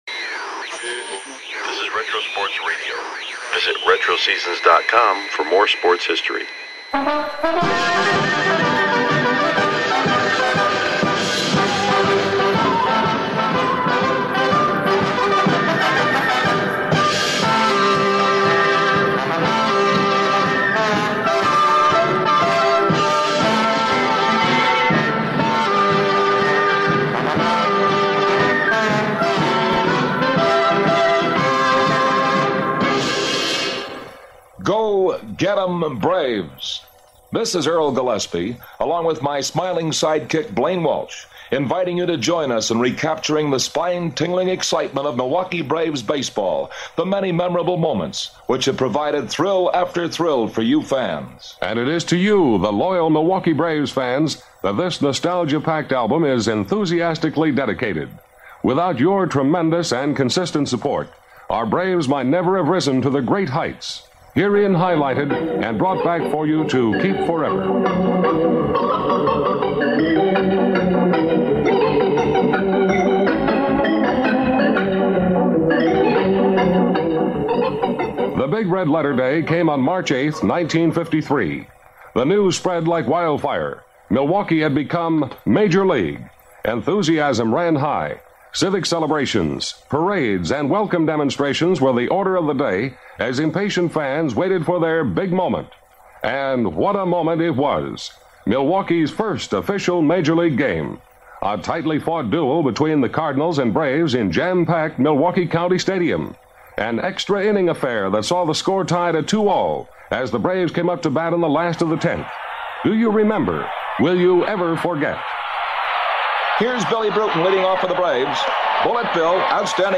1940-Oct-08 • DET/CIN • World Series G7 • Detroit Tigers vs Cincinnati Reds - Classic Baseball Radio Broadcast – Retro Sports Radio: Classic Games from History – Lyssna här
Venue: Crosley Field - Radio Announcers - Red Barber - Bob Elson